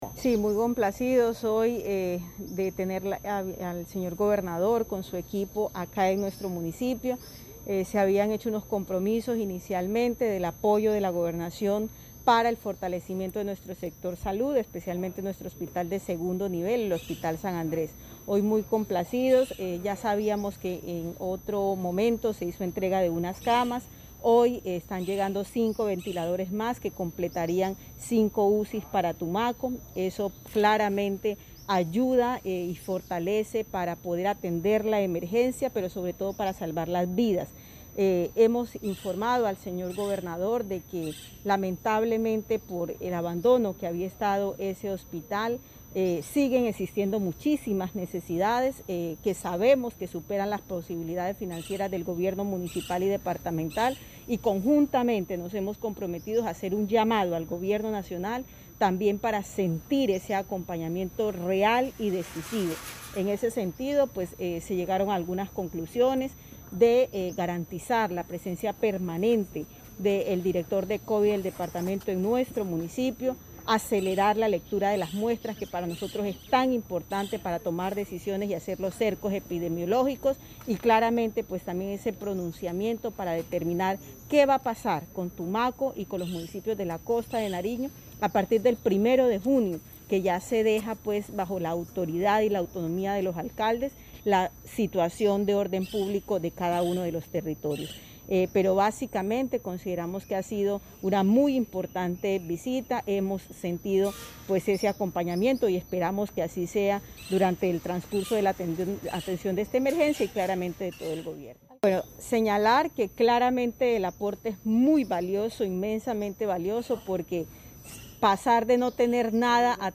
Declaración de la alcaldesa de Tumaco Emilsen Angulo